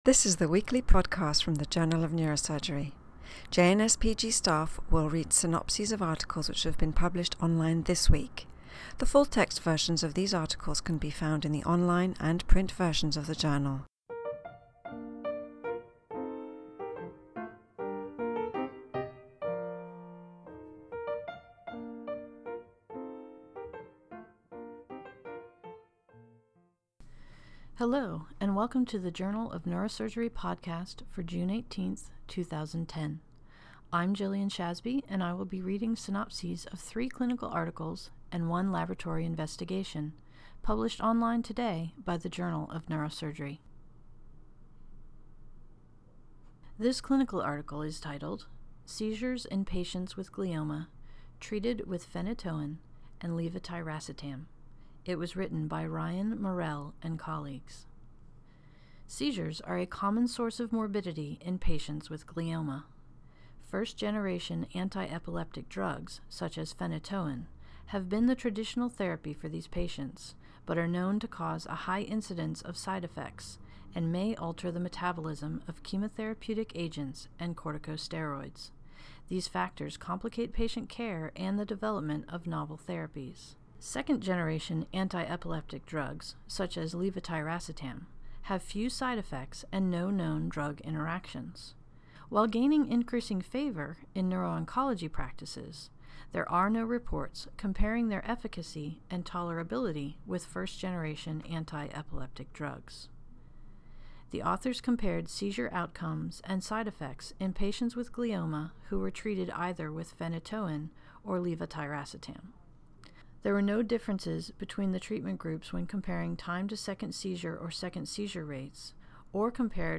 reads synopses of Journal of Neurosurgery articles published online on June 18, 2010.